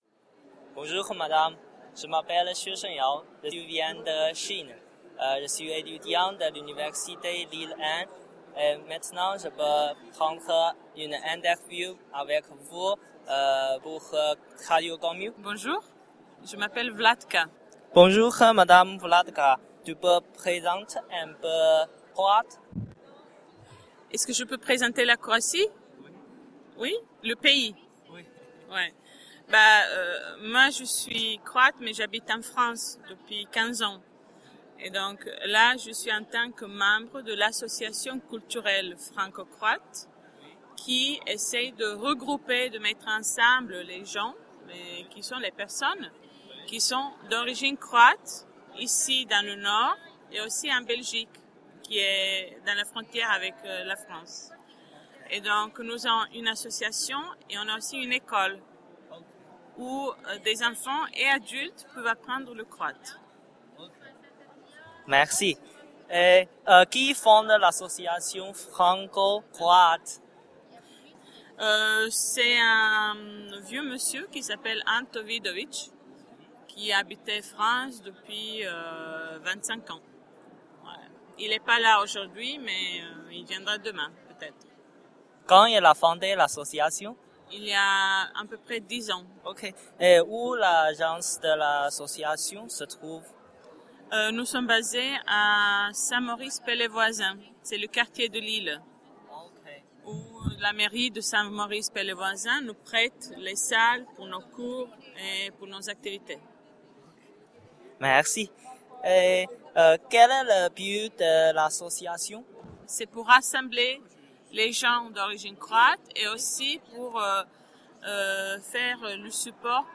Cette 7ème édition du festival des langues a eu lieu les 8 et 9 avril 2011 à la Chambre de Commerce et d'Industrie Grand Lille
L'équipe était constituée d'étudiants chinois de Lille 1